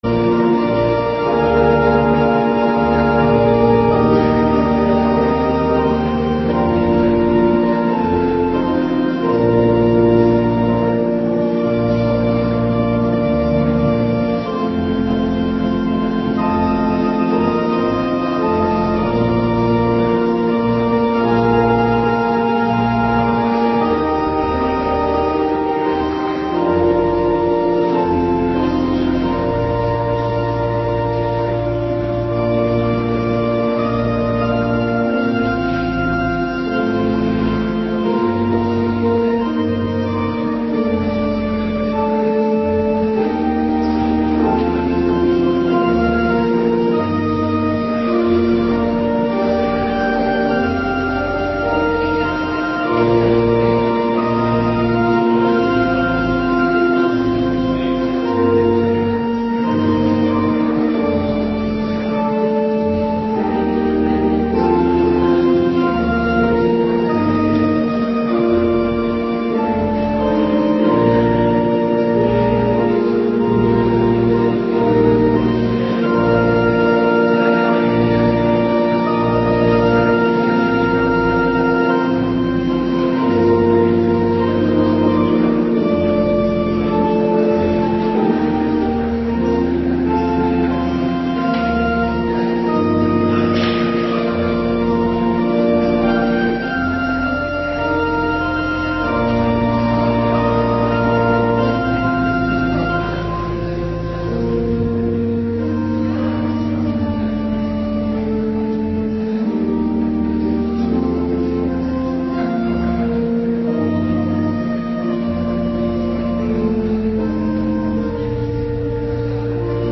Avonddienst 11 maart 2026